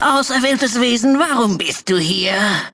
Kategorie:Fallout 2: Audiodialoge Du kannst diese Datei nicht überschreiben.